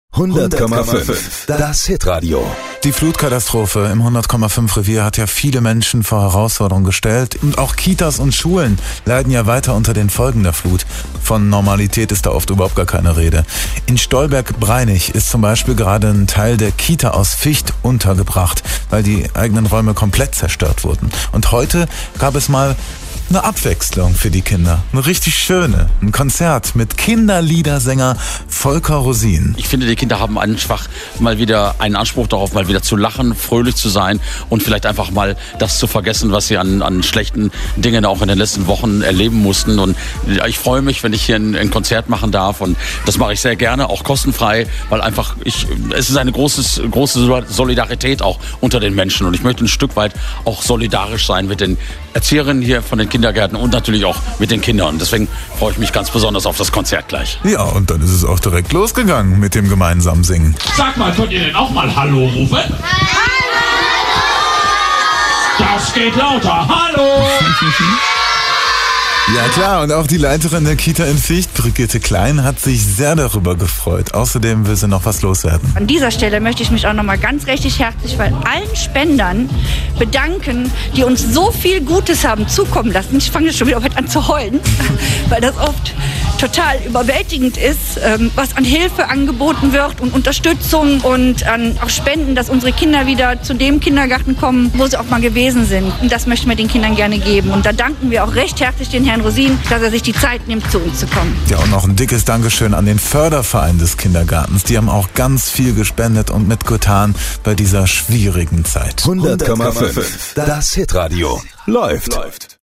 Hört hier auch die Reportage von 100,5 Das Hitradio